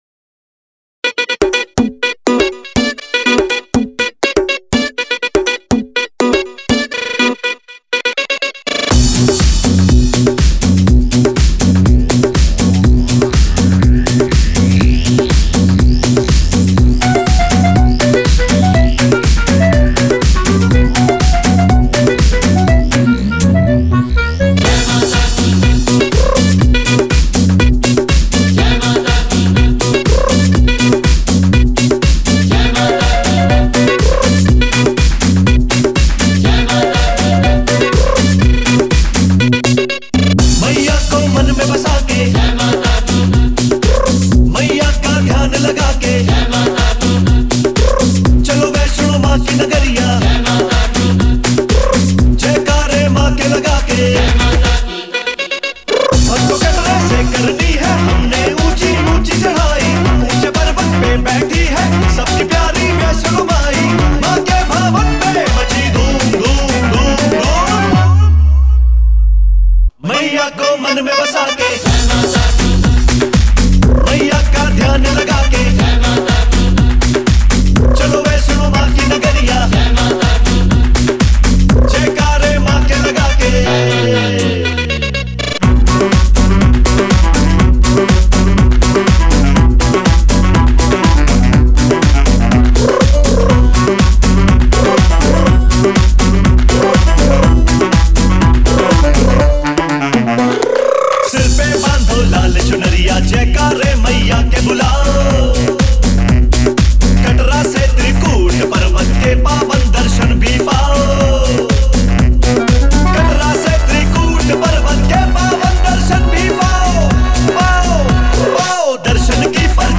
bhajan album